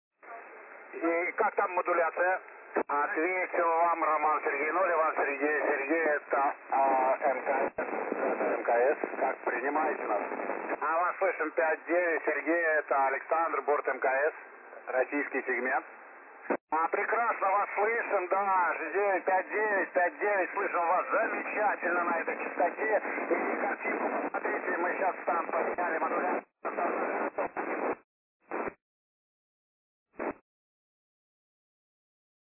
После неудачных попыток передать картинки, неисправность была устранена, подняли модуляцию.
Начало » Записи » Записи радиопереговоров - МКС, спутники, наземные станции
Запись с частоты 145,800 FM.